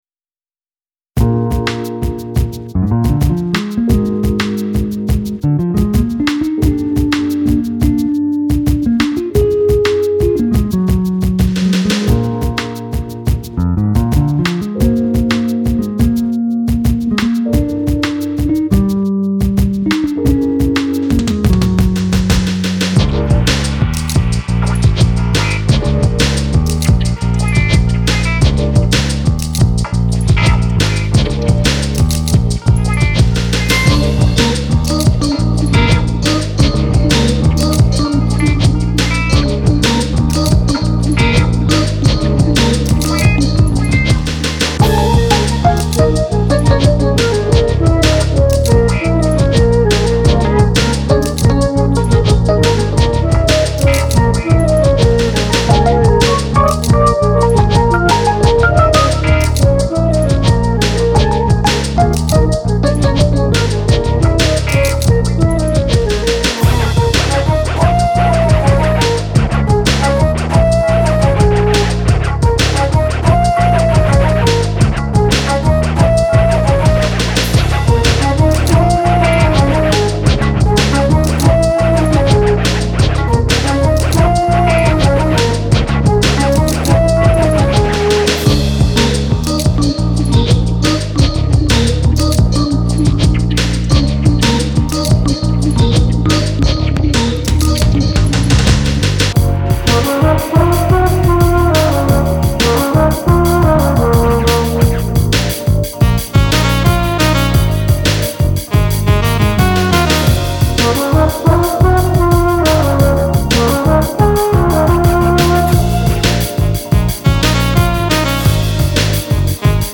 Genre: DowntempoTrip-Hop.